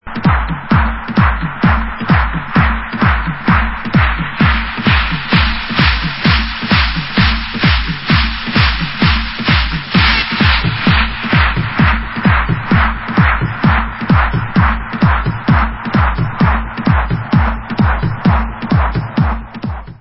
Vocal house